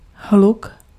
Ääntäminen
Tuntematon aksentti: IPA : /nɔɪz/